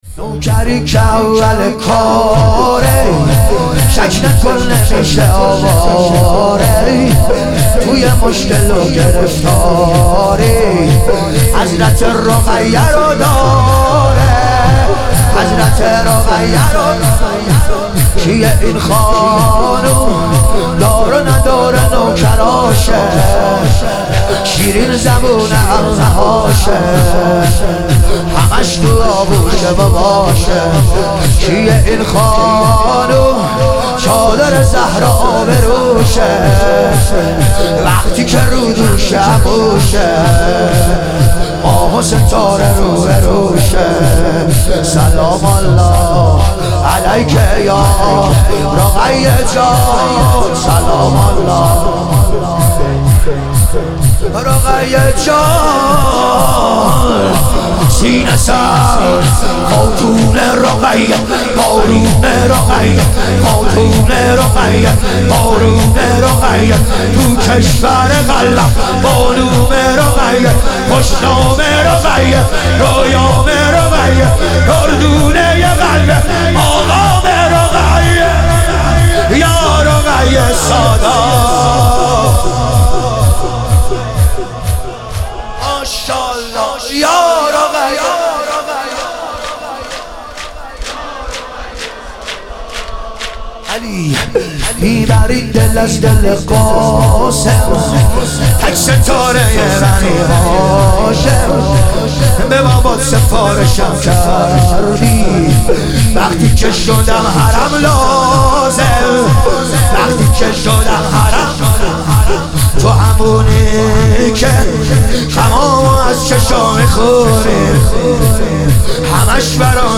شهادت امام حسن مجتبی علیه السلام - شور